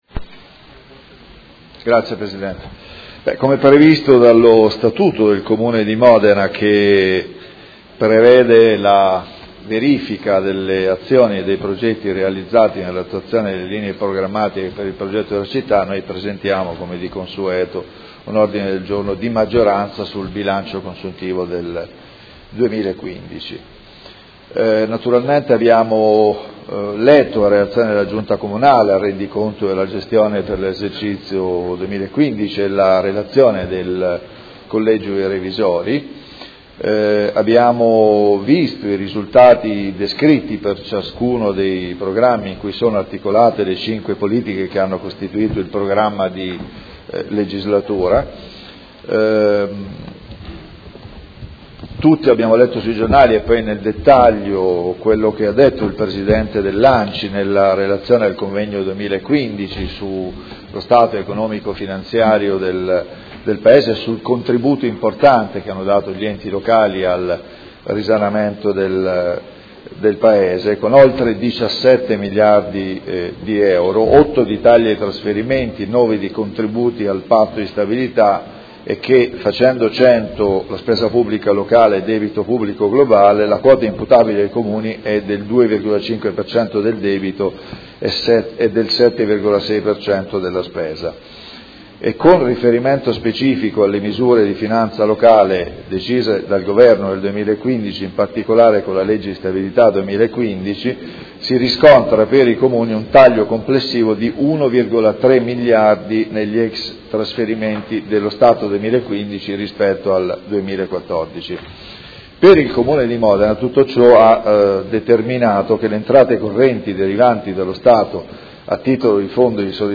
Seduta del 28/04/2016. Presentazione odg n°63618 Bilancio consuntivo 2015
Audio Consiglio Comunale